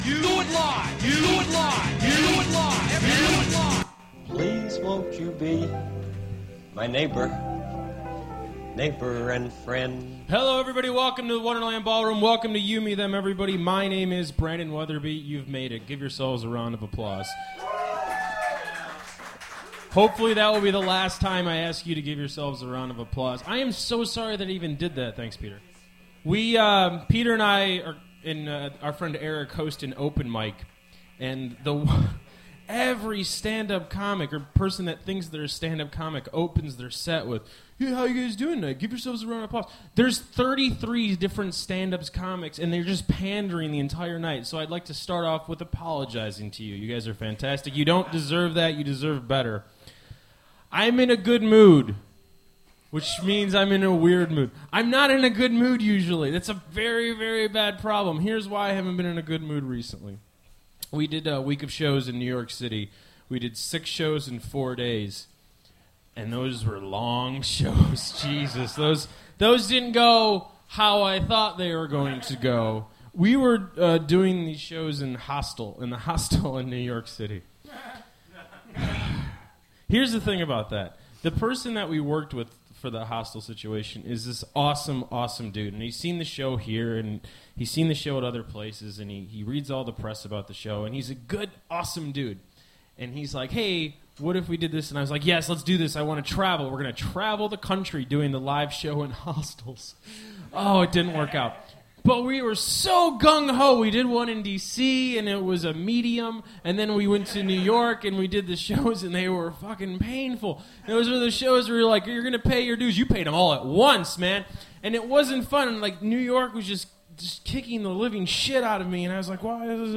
old fashioned joke writer that is good and not old fashioned. noon:30 is a two piece that does not sound like a two piece. Back at the Wonderland.